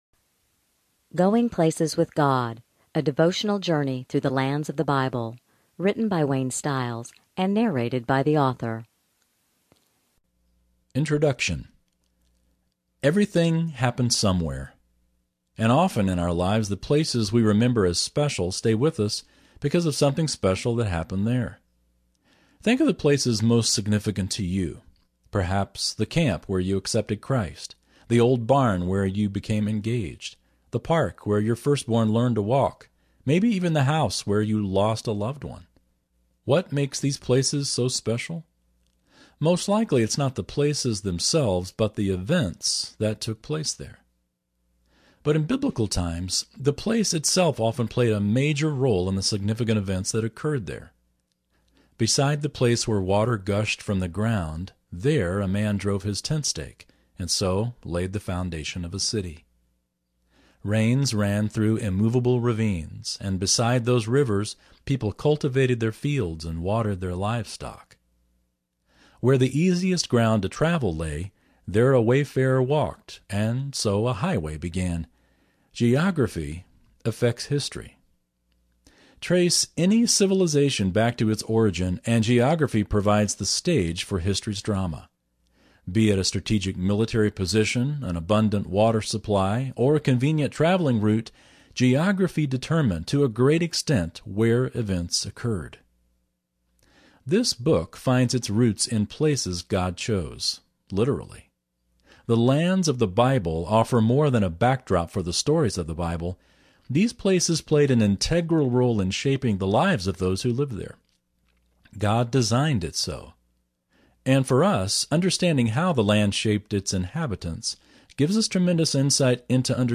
Going-Places-With-God-Audiobook-SAMPLER.mp3